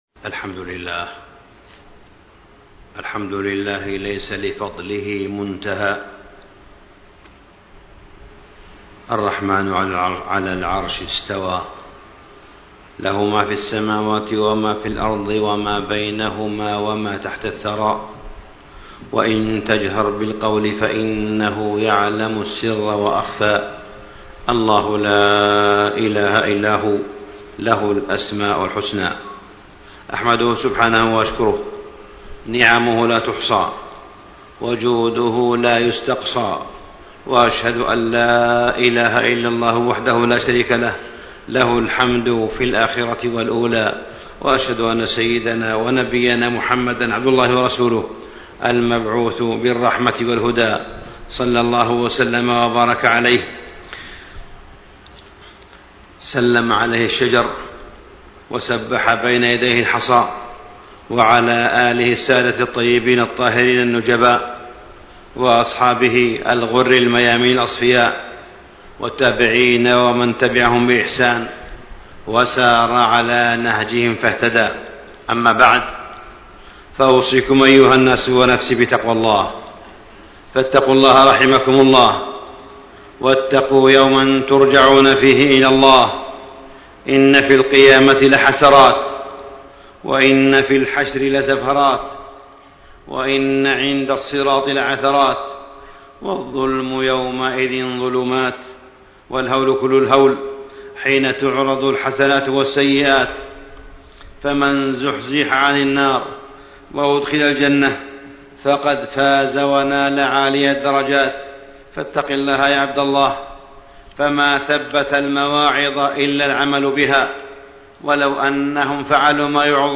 تاريخ النشر ٢٧ جمادى الأولى ١٤٣٥ هـ المكان: المسجد الحرام الشيخ: معالي الشيخ أ.د. صالح بن عبدالله بن حميد معالي الشيخ أ.د. صالح بن عبدالله بن حميد خطورة الجرائم الخلقية